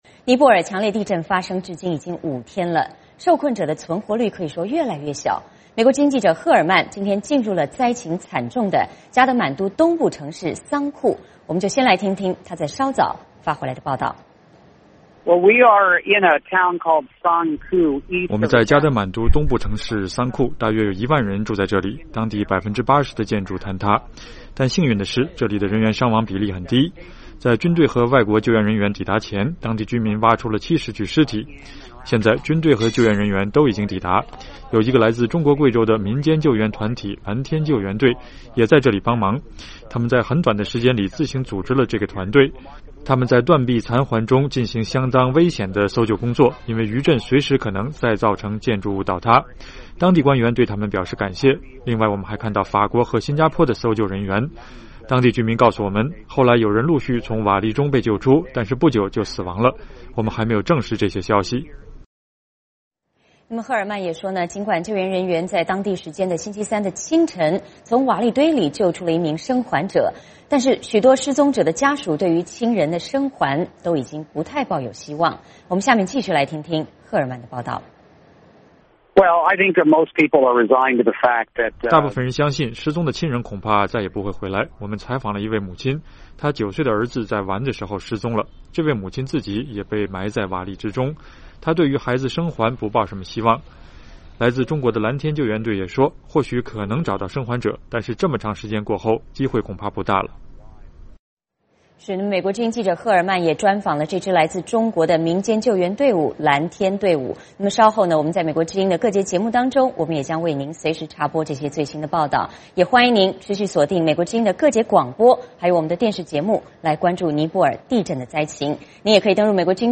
我们来听听他在稍早前发来的报道。